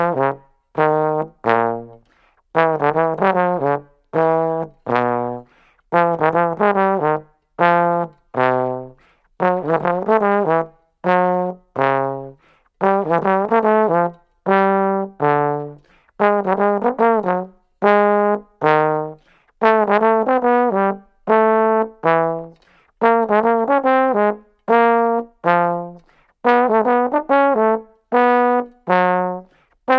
Voicing: Jazz Tromb